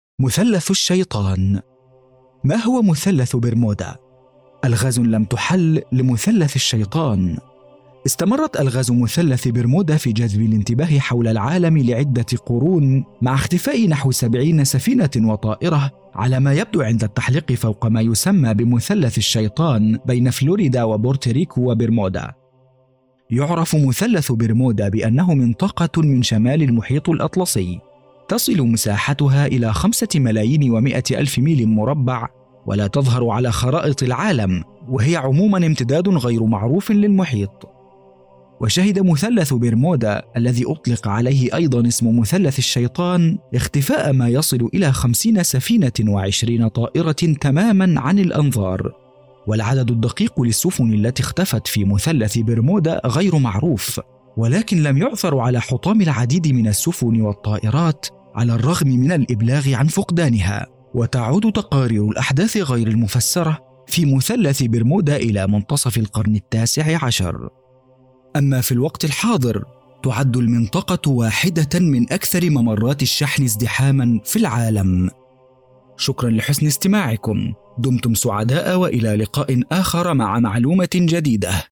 مثلث برمودا – وثائقي
• ذكر
• وثائقي
• العربية الفصحى
• باريتون Baritone (متوسط العرض)
• في منتصف العمر ٣٥-٥٥